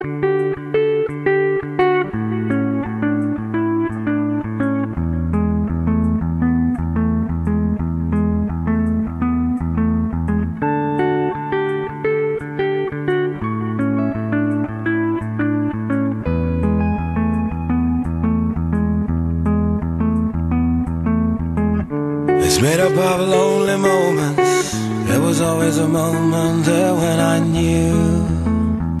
Catégorie Électronique